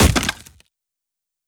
Hit_Wood 06.wav